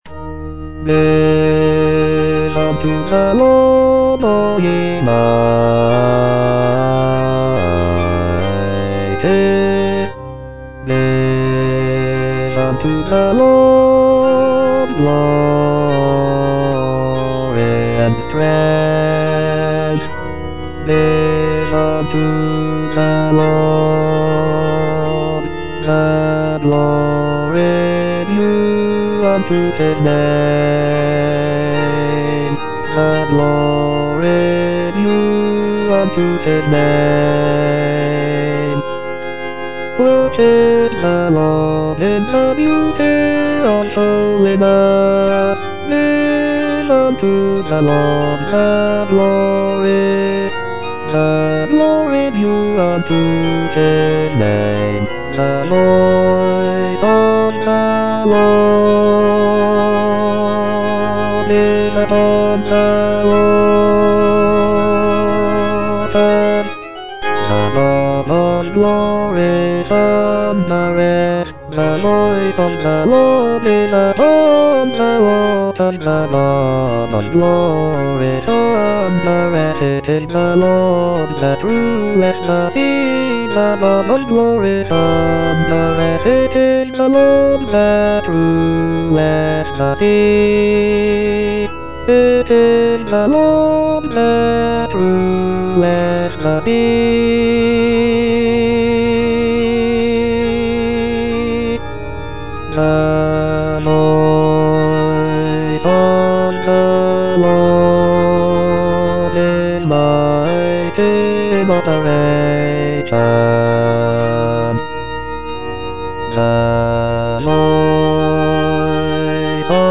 E. ELGAR - GIVE UNTO THE LORD Bass II (Voice with metronome) Ads stop: auto-stop